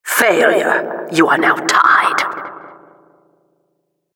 announcer_plr_secondstageoutcome03